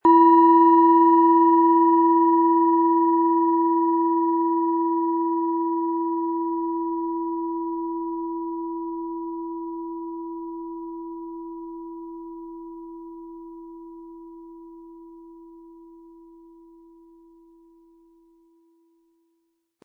Tibetische Herz-Bauch-Kopf- und Fuss-Klangschale, Ø 15,4 cm, 320-400 Gramm, mit Klöppel
HerstellungIn Handarbeit getrieben
MaterialBronze